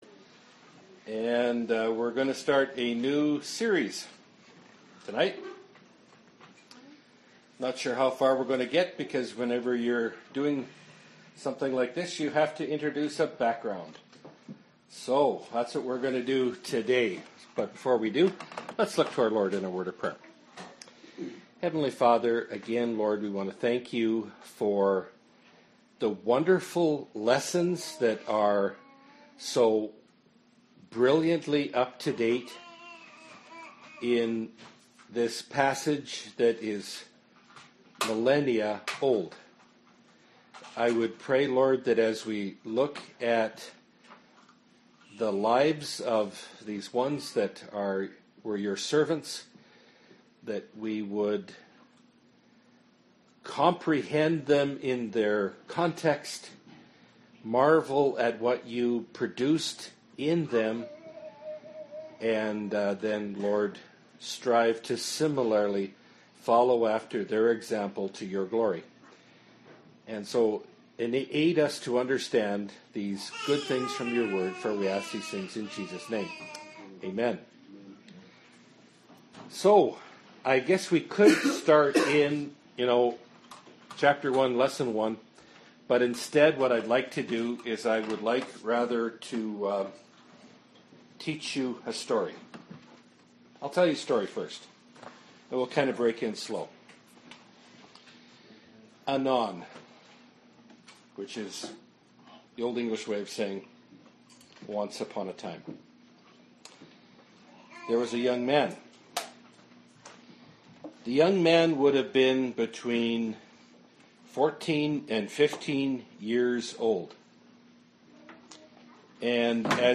Bible Study – Daniel 1 – Part 1 of 2 (2017)